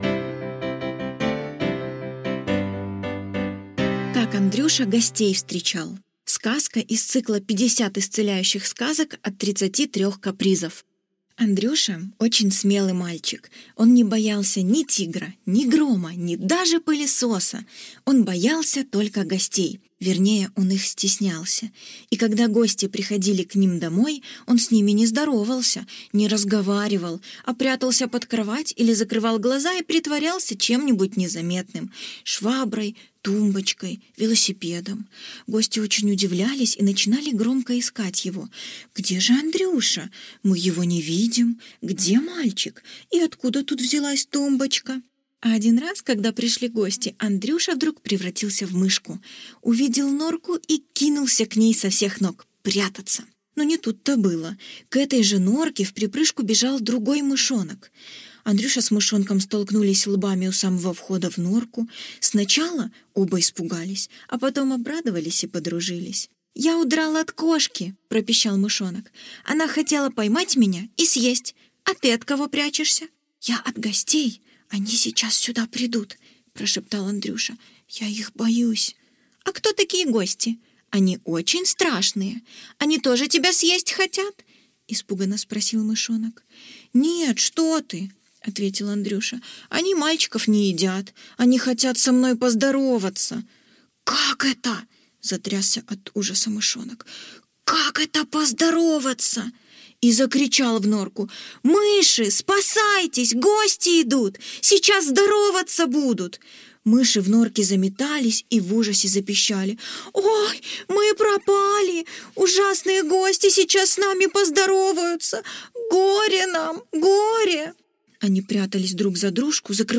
Аудиосказка «Как Андрюша гостей встречал»